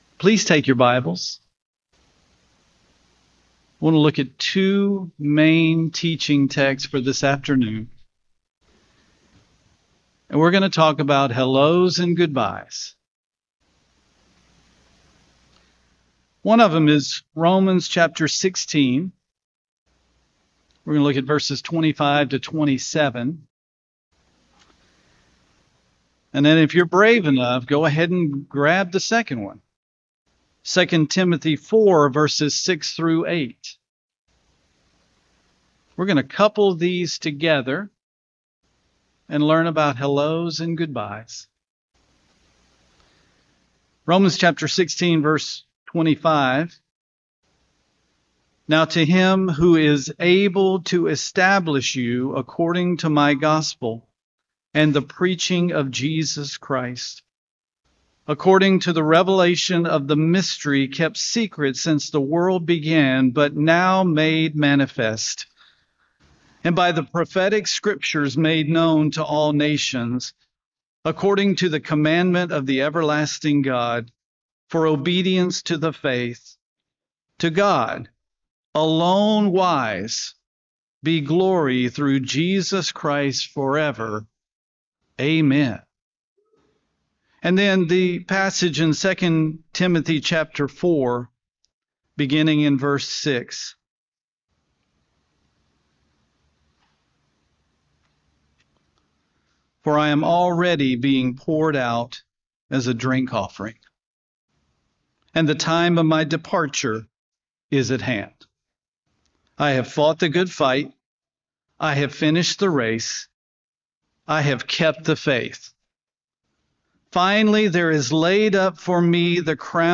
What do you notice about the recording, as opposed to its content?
Given in Aransas Pass, Texas